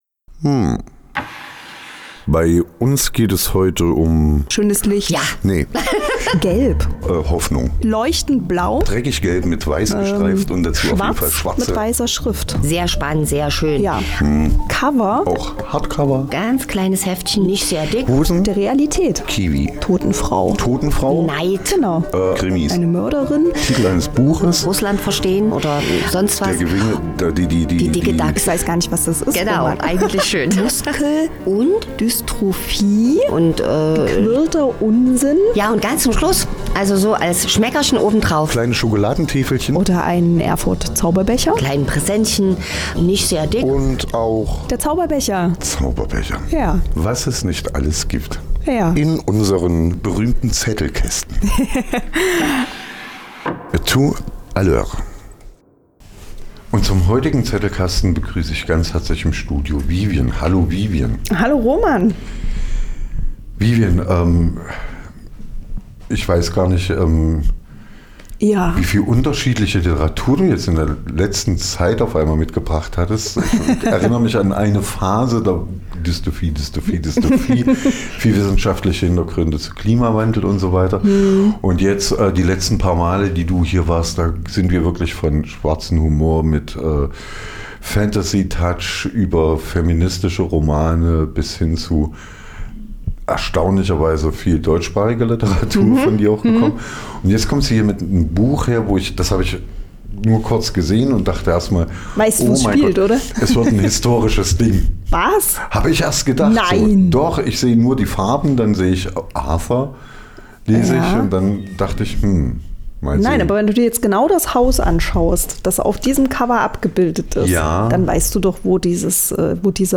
Zettelkasten - die aktuelle Buchbesprechung | Liz Moore - Der andere Arthur